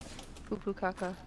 Play, download and share Poo Poo Caca original sound button!!!!